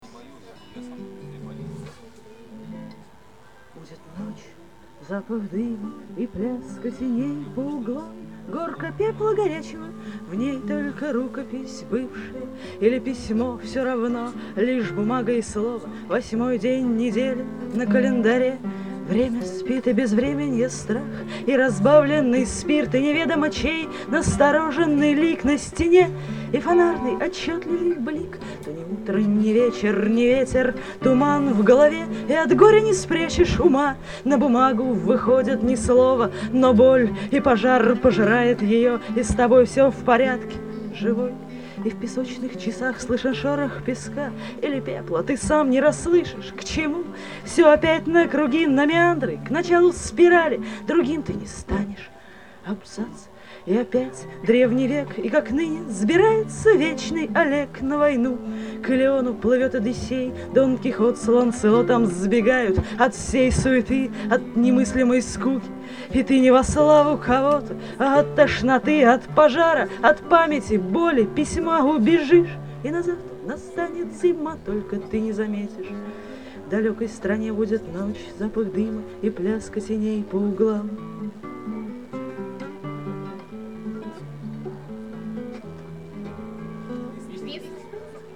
Запись фрагмента Шляпной Мастерской в лагере ТА "32-е августа" на Грушинском-2002